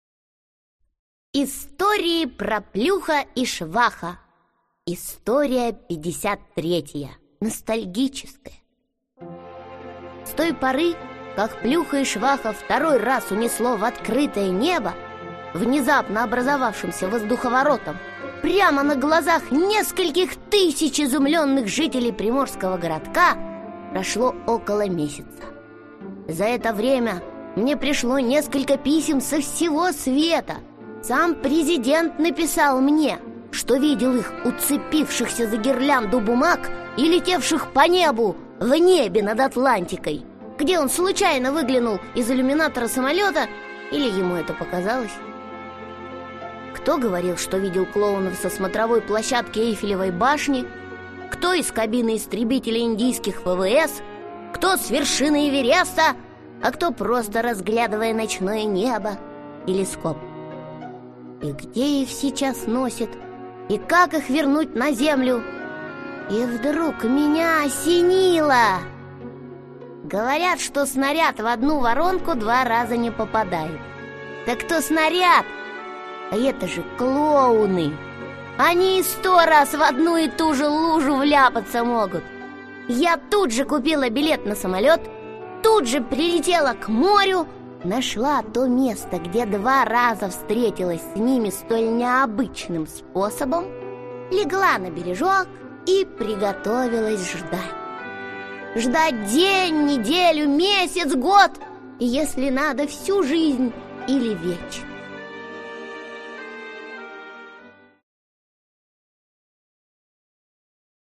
Аудиокнига Мы чемпионы | Библиотека аудиокниг